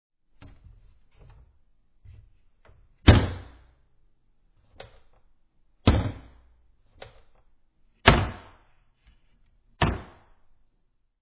door3.mp3